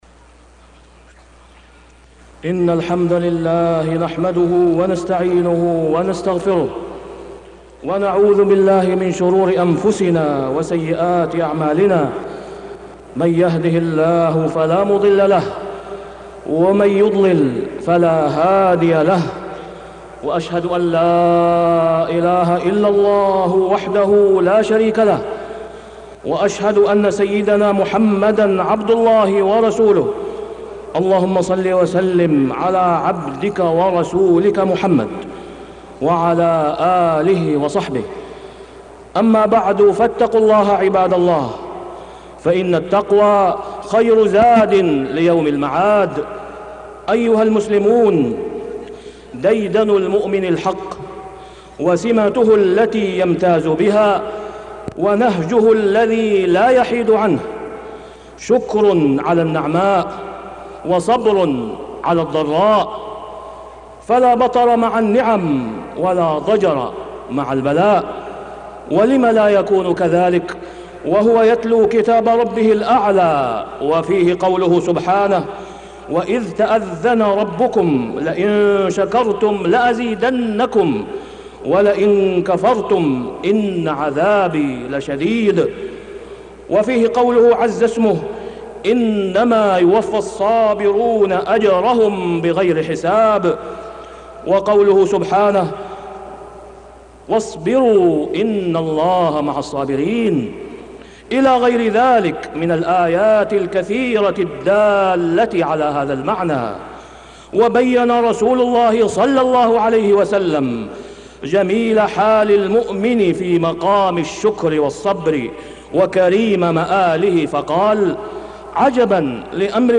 تاريخ النشر ٢ ربيع الأول ١٤٢٧ هـ المكان: المسجد الحرام الشيخ: فضيلة الشيخ د. أسامة بن عبدالله خياط فضيلة الشيخ د. أسامة بن عبدالله خياط بين الشكر والصبر